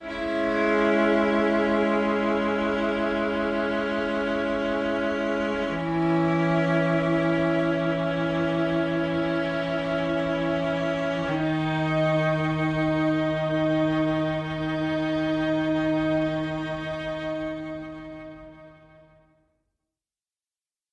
Descarga del portal el archivo de sonido violín.
08violin.mp3